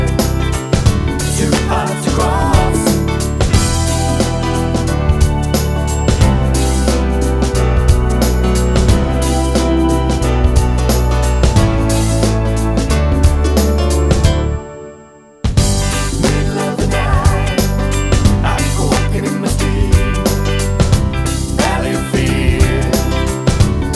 no piano Pop (1980s) 3:55 Buy £1.50